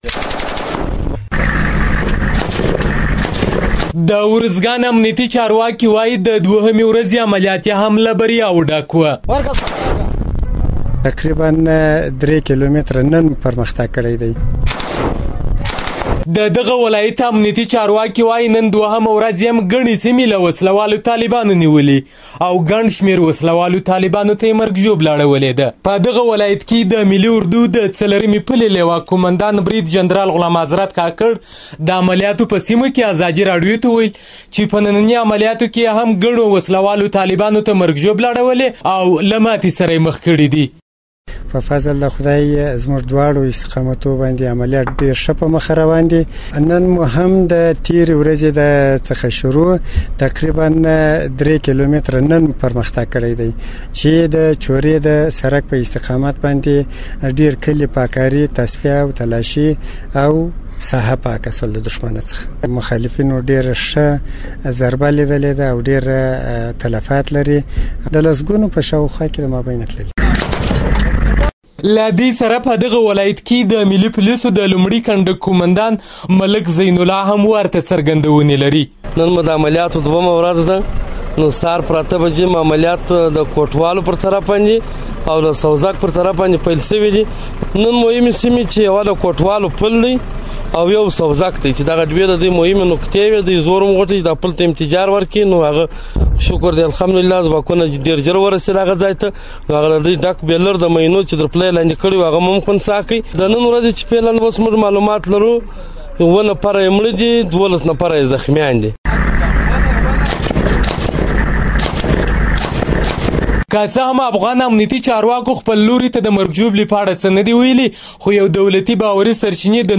راپور له دې برخې دی.